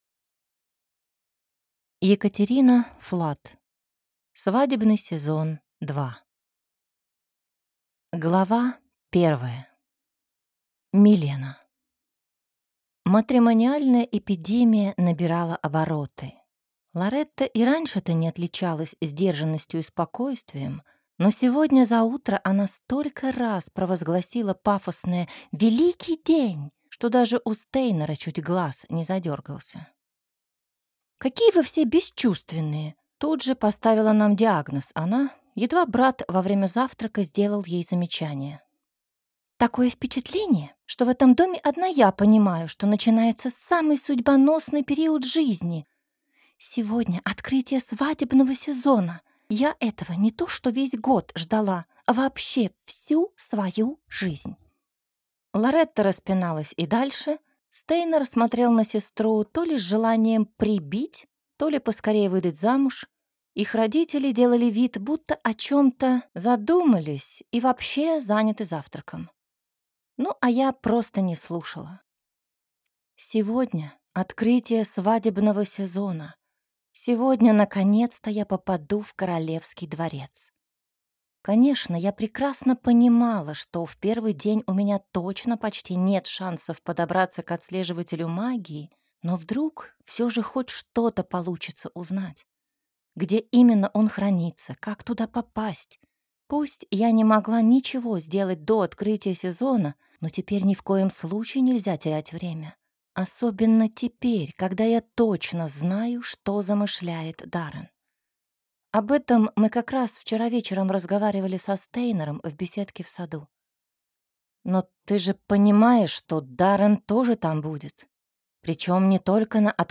Аудиокнига Свадебный сезон – 2 | Библиотека аудиокниг